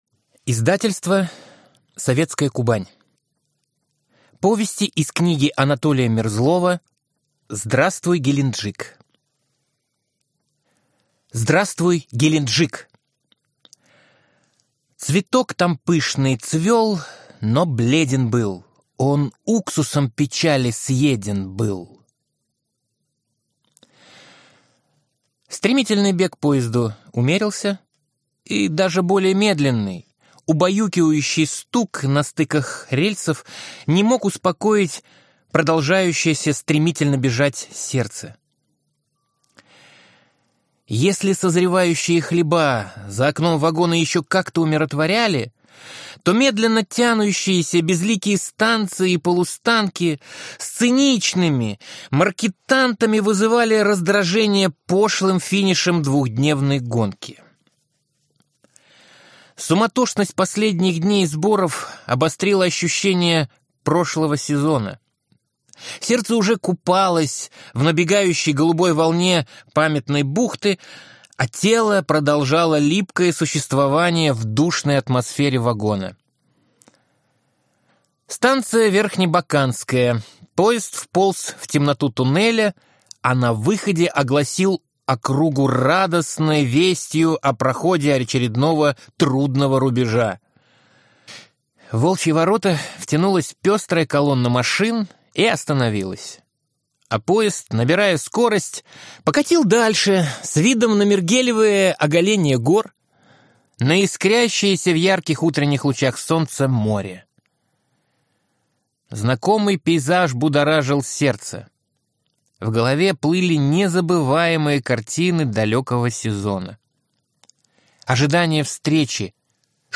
Аудиокнига Здравствуй, Геленджик!